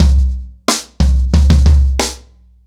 Wireless-90BPM.51.wav